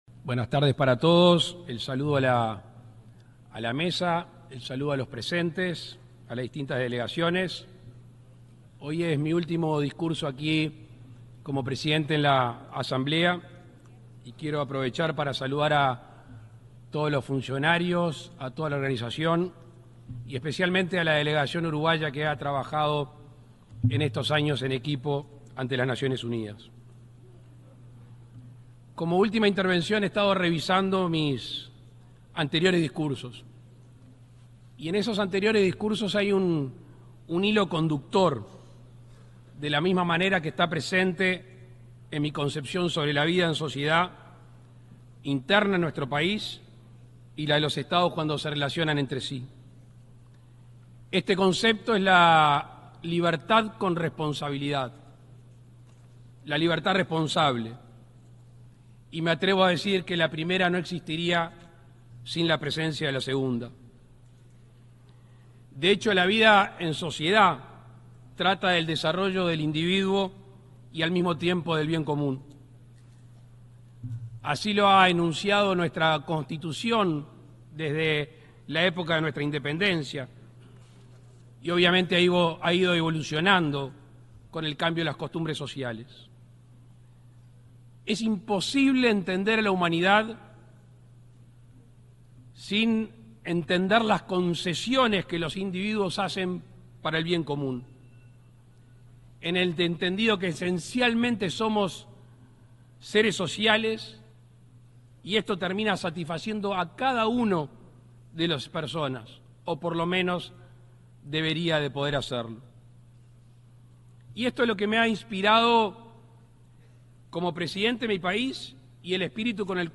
Disertación del presidente Luis Lacalle Pou
Disertación del presidente Luis Lacalle Pou 26/09/2024 Compartir Facebook X Copiar enlace WhatsApp LinkedIn Este jueves 26, el presidente Luis Lacalle Pou, disertó en la 79.ª sesión de la Asamblea General de la Organización de las Naciones Unidas (ONU), que se realiza en la ciudad de Nueva York, Estados Unidos.